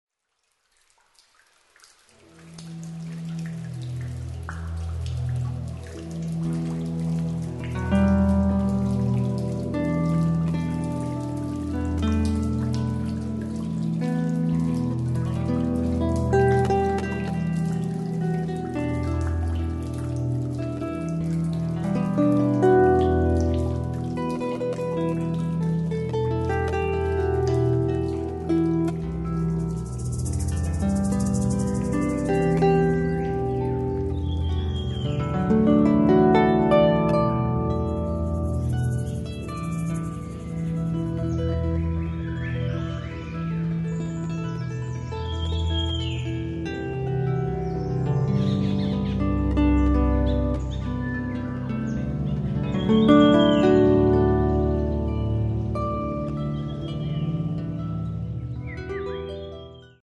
Schlaflieder aus aller Welt (Entspannungsmusik)
Keltische Harfe, Gesang
Klavier, Percussion, Gesang, Flöten, Keyboards
Violoncello